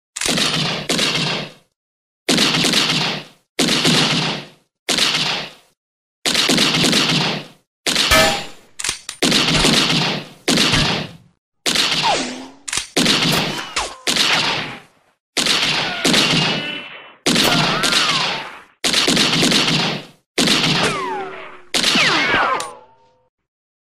Shotgun Reload Pump
This is an awesome and excellent quality reloaded of an mossberg 590 shotgun